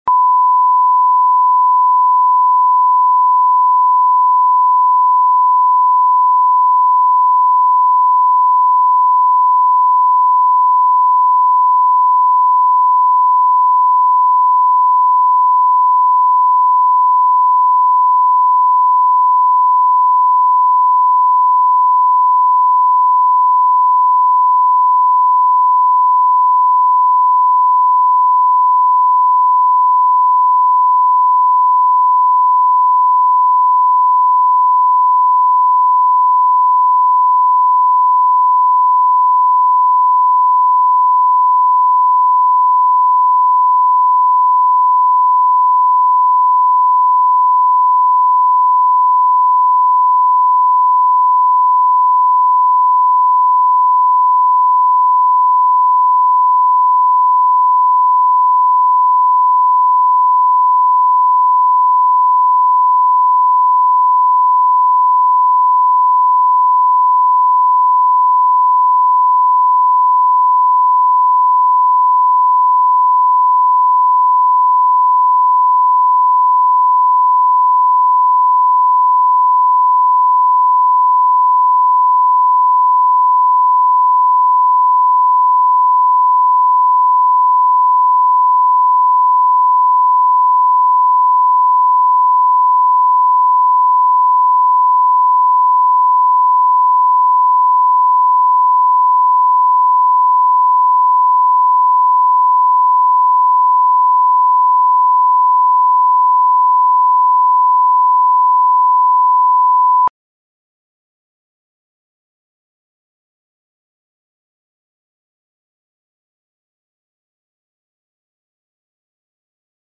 Аудиокнига Гостевой брак | Библиотека аудиокниг
Прослушать и бесплатно скачать фрагмент аудиокниги